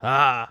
Male_Grunt_Curious_07.wav